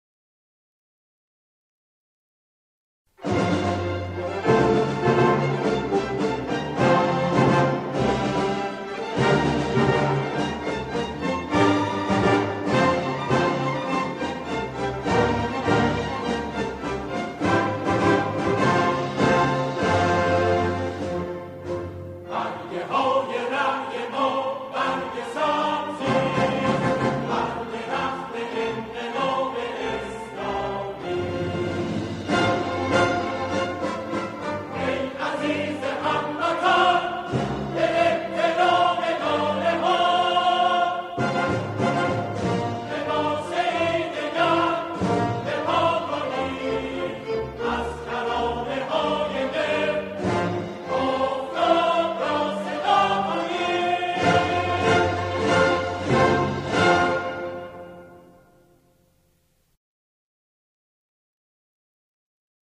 همخوانی شعری کوتاه